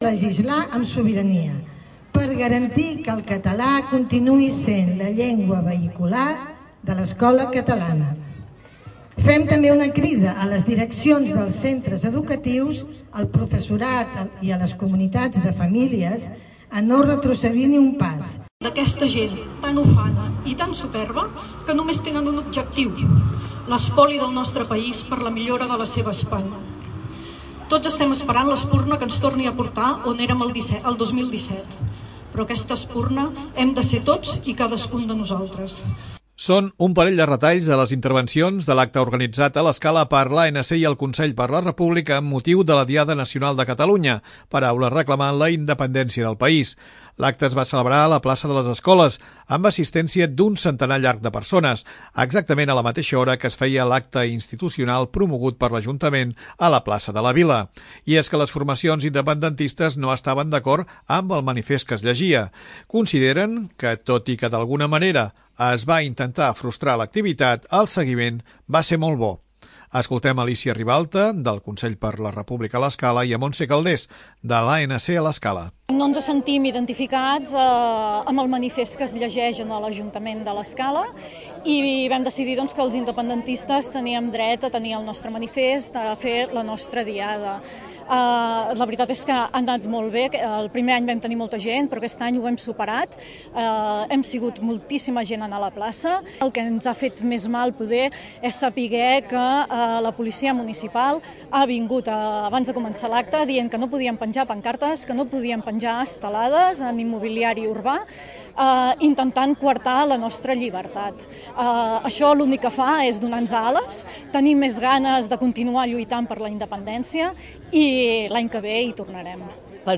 Són un parell de retalls de les intervencions de l'acte organitzat a l'Escala per l'ANC i el Consell per la República amb motiu de la Diada Nacional de Catalunya,  paraules reclamant la independència del país.
L'acte es va celebrar a la Plaça de les Escoles, amb l'assistència d'un centenar llarg de persones,  exactament a la mateixa hora que es feia l'acte institucional promogut per l'ajuntament a la Plaça de la Vila.